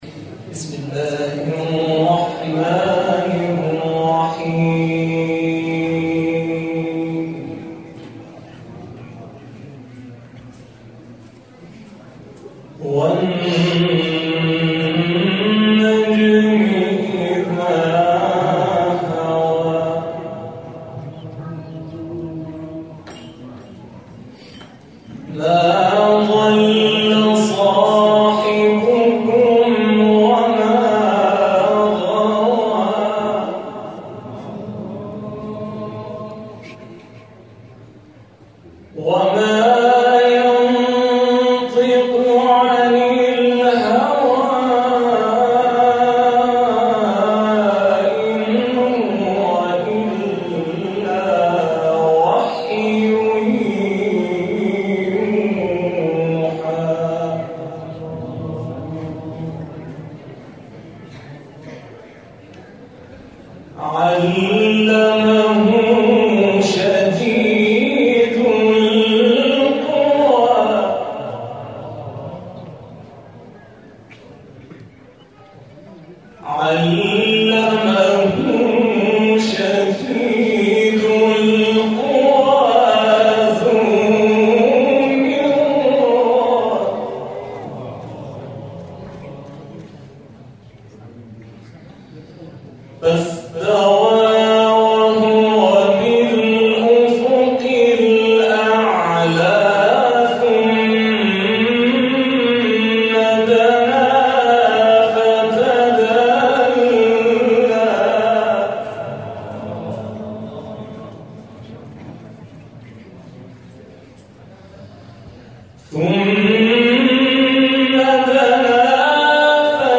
به گزارش خبرگزاری بین‌المللی قرآن(ایکنا)،  اعضای دارالقرآن نفحات، روز گذشته، هشتم مرداد ماه، با حضور در بیت آیت‌الله‌العظمی جوادی آملی واقع در دماوند ، باایشان دیدار و در روضه شب شهادت امام صادق(ع) شرکت کردند.